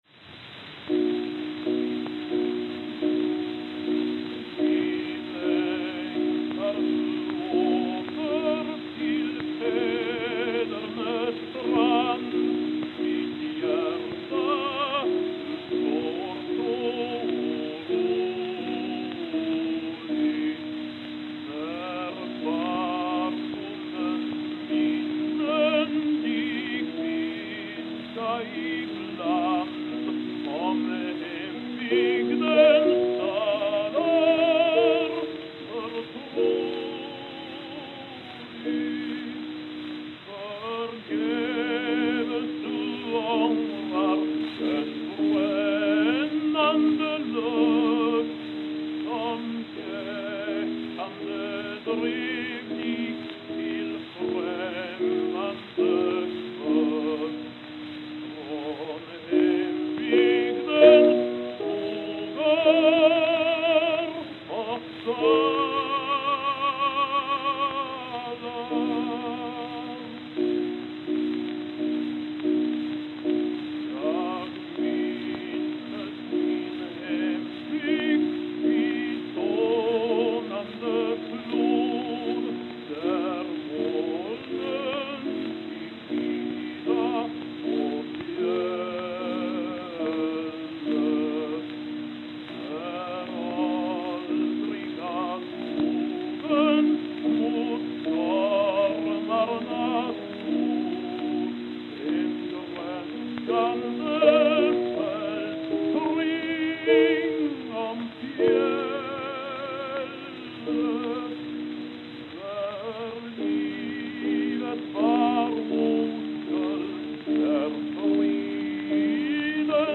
Stockholm, Sweden Stockholm, Sweden
Note: Low volume on master.